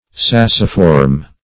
Search Result for " sacciform" : The Collaborative International Dictionary of English v.0.48: Sacciform \Sac"ci*form\, a. [L. saccus a sack + -form.]